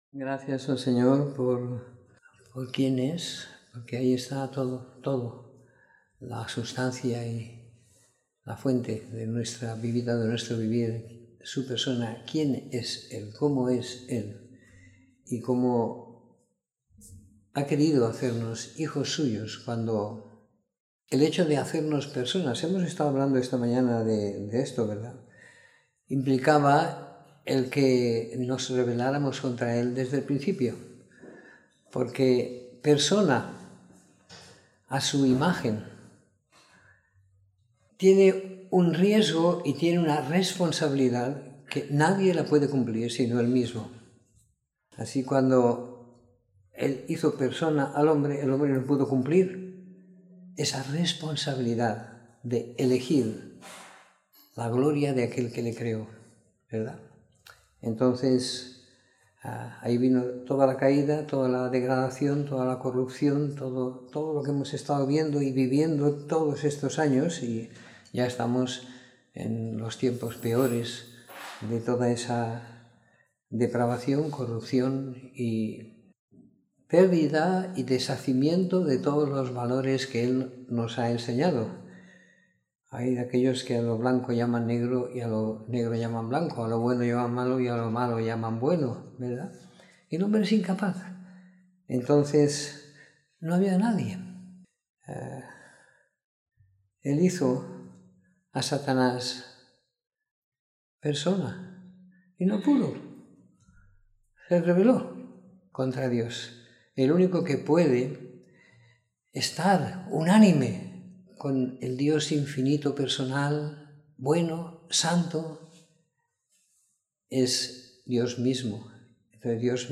Domingo por la Tarde . 10 de Diciembre de 2017